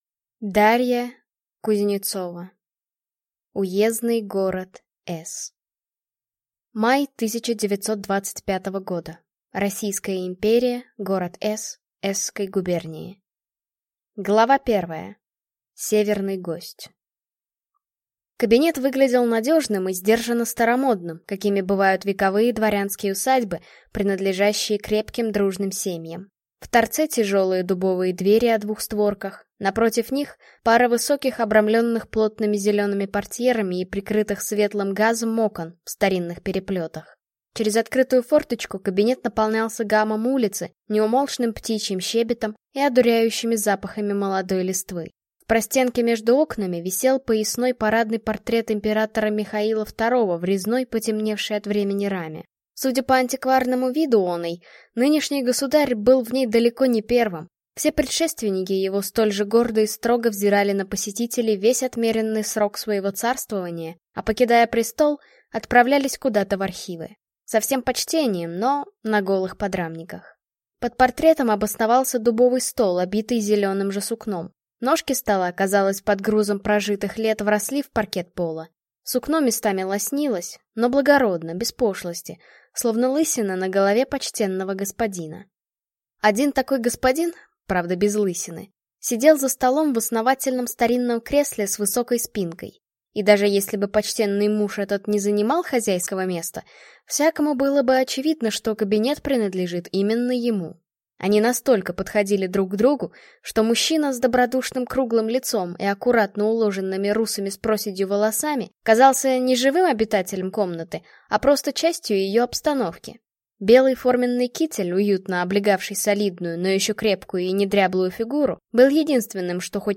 Аудиокнига Уездный город С*** | Библиотека аудиокниг